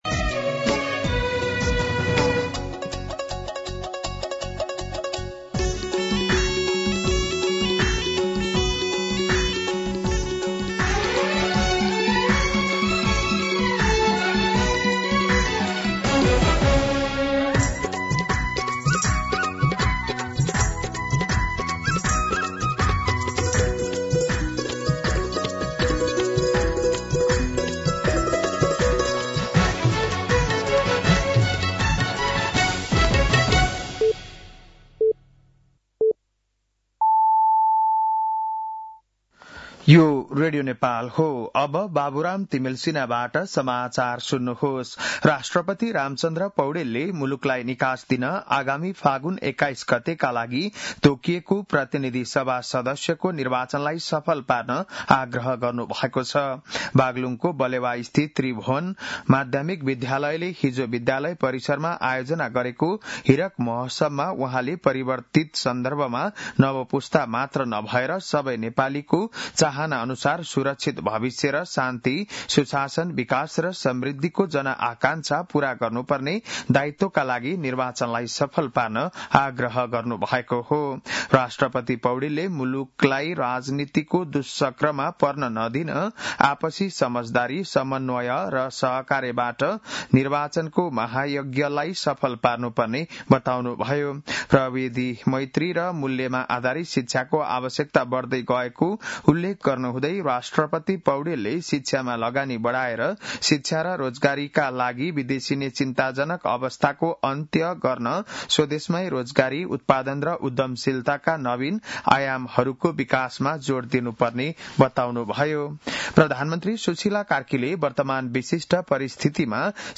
बिहान ११ बजेको नेपाली समाचार : २ माघ , २०८२